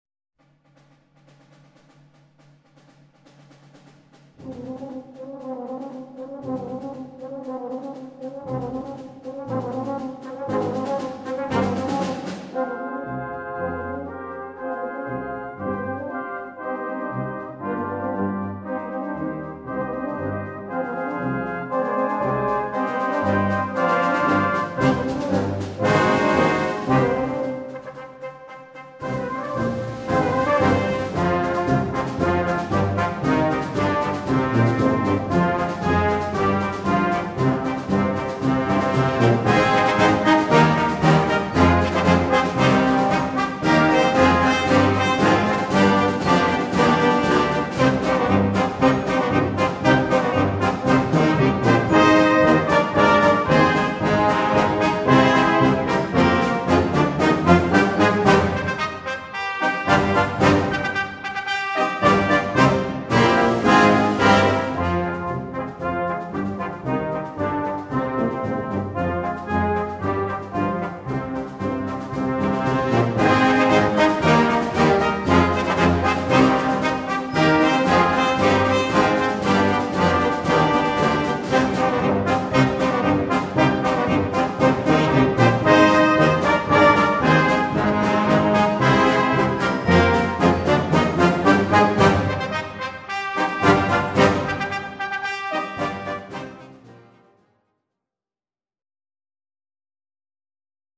Russian Folksong = Marschbuchformat
Besetzung: Blasorchester